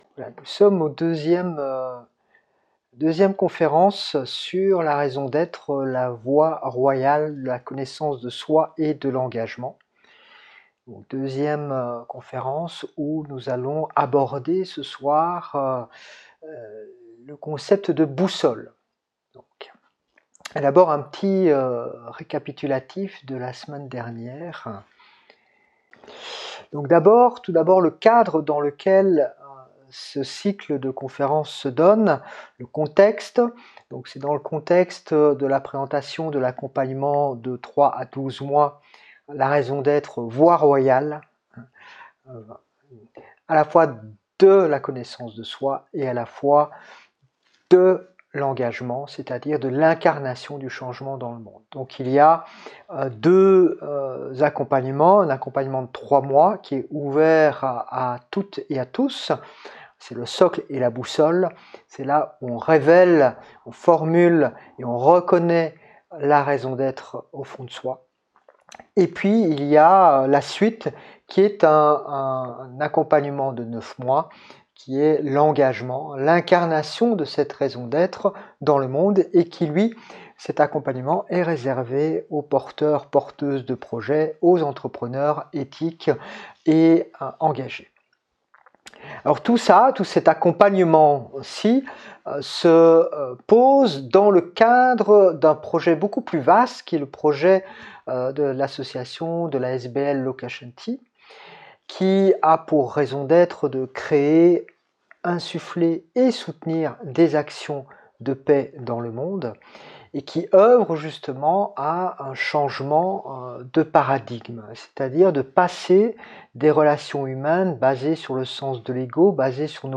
Enregistrement de l’entrevue du 26 mars